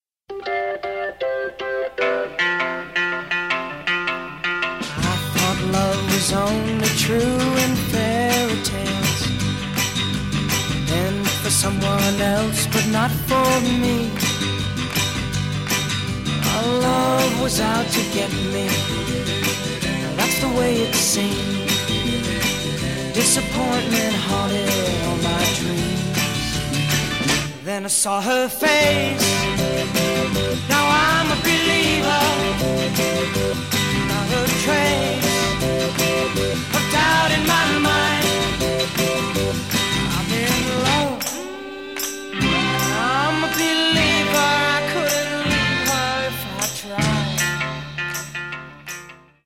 OLD SCHOOL ROCK no. 3